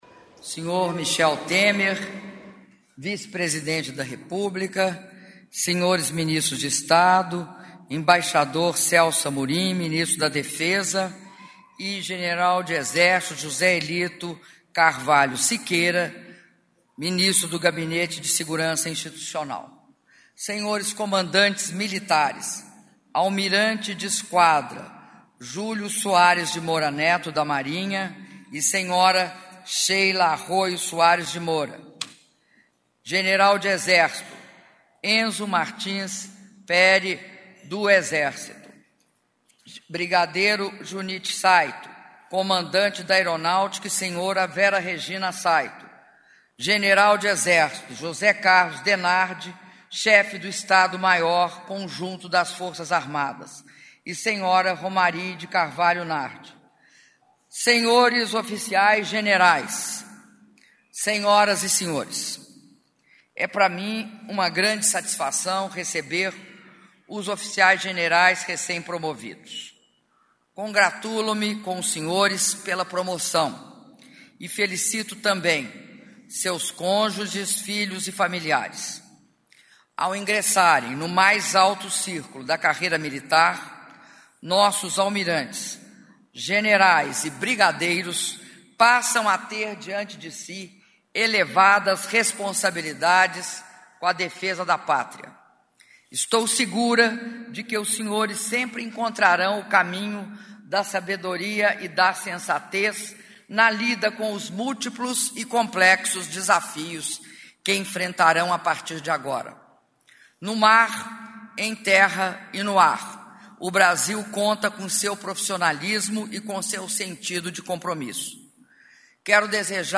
Áudio do discurso da Presidenta da República, Dilma Rousseff, na solenidade de apresentação de oficiais-generais recém-promovidos - Brasília/DF (2min11s)